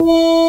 Sylense_Chord001.wav